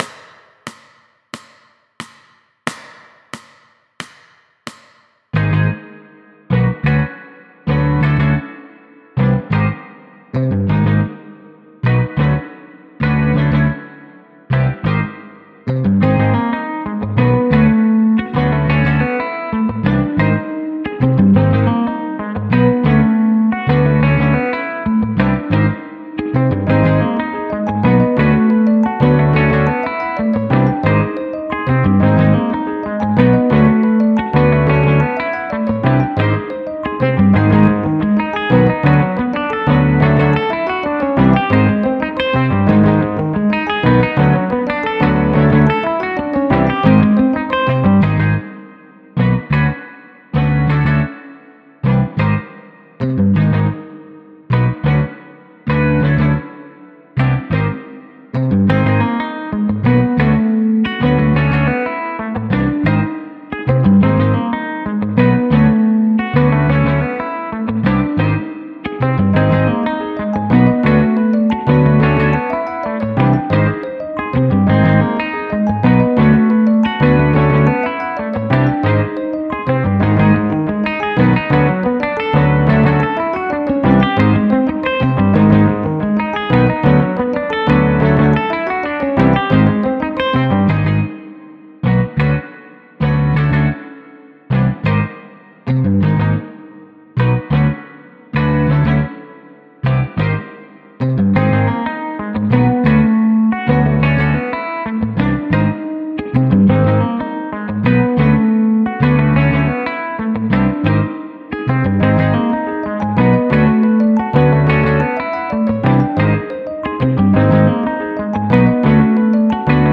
90 BPM without click